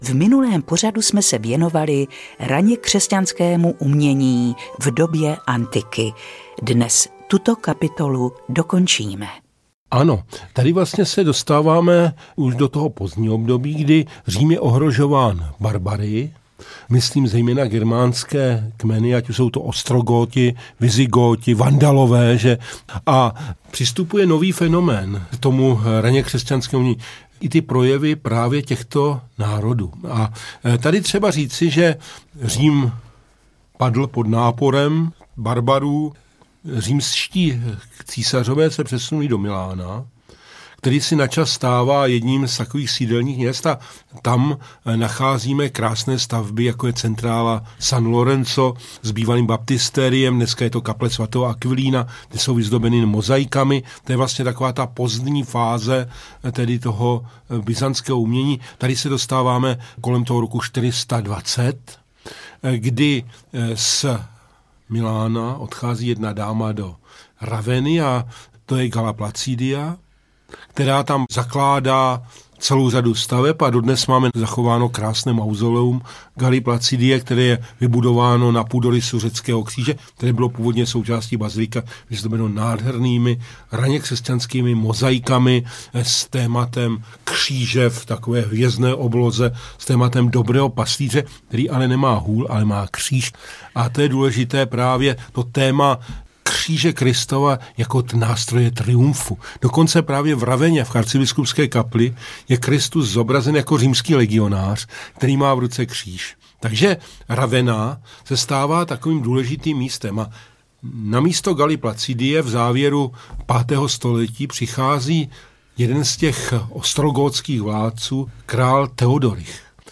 Dějiny křesťanského výtvarného umění audiokniha
Ukázka z knihy